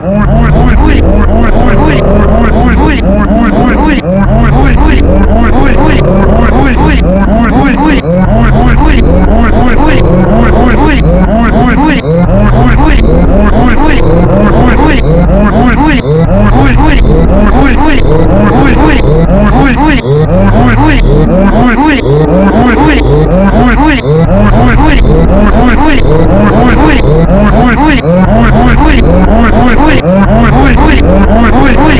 Hrk S Lag Tester 2 Bowling Strike